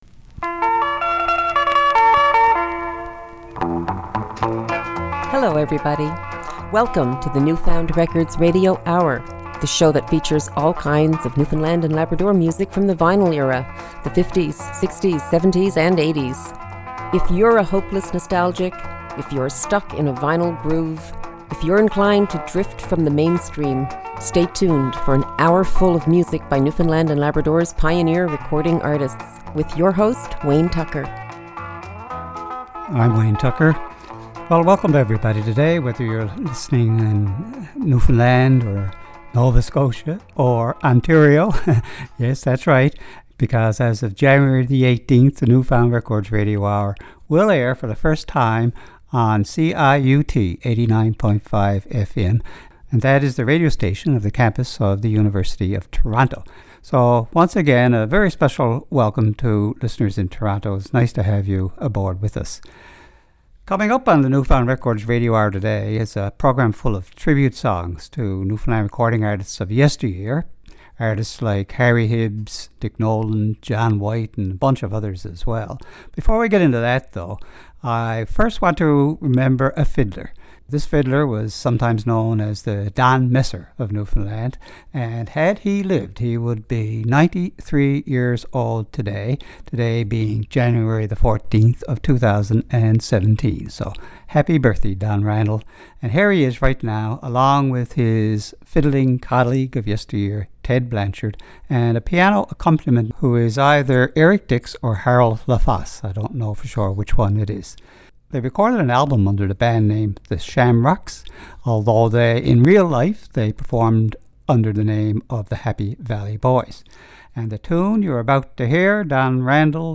Vinyl records by Newfoundland & Labrador's pioneer recording artists.
Recorded at the CHMR Studios, Memorial University, St. John's, NL.